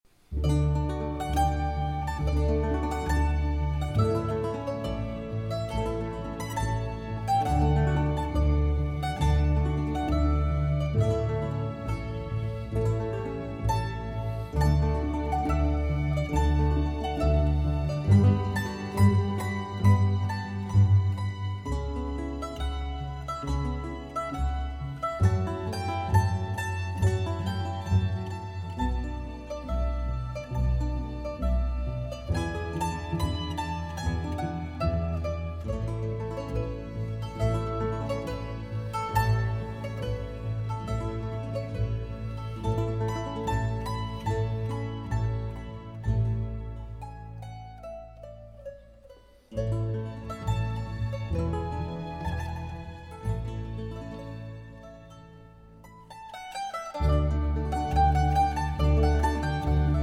especially with Italian Baroque music.
Mandolin